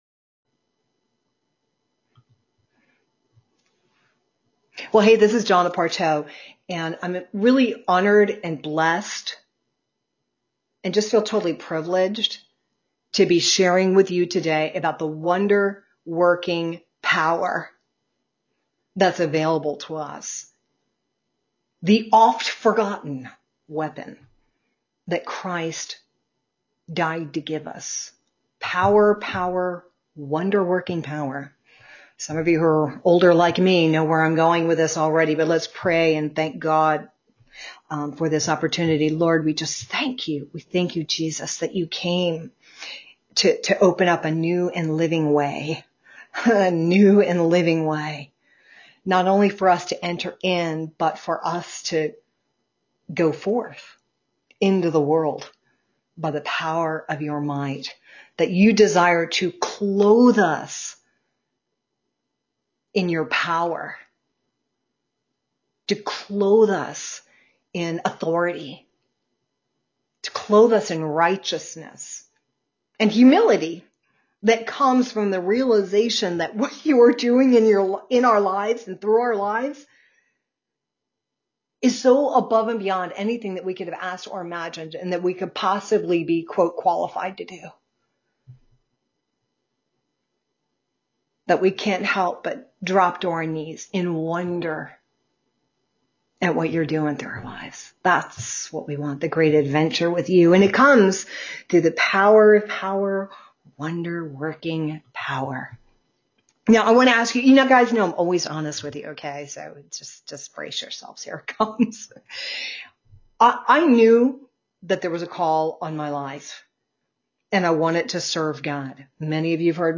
Applying-The-Blood-teaching.mp3